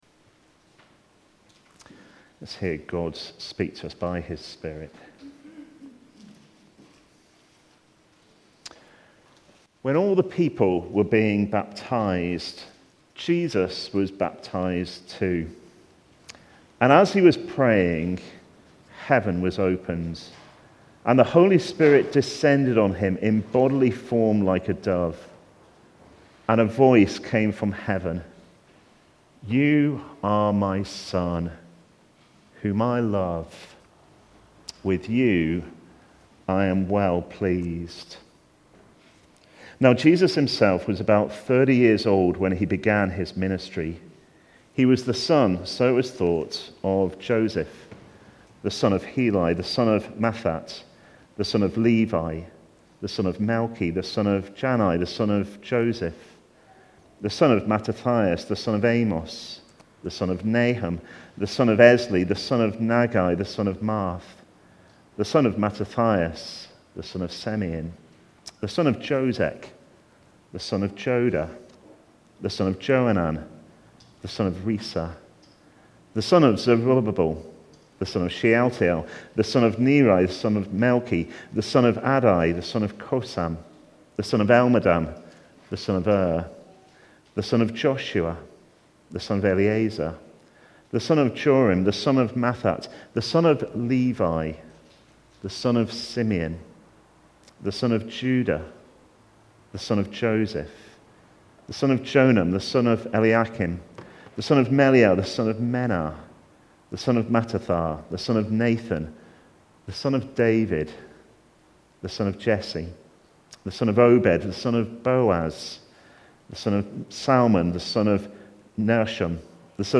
Jesus' Baptism (Luke 3:21-38) Recorded at Woodstock Road Baptist Church on 25 April 2021.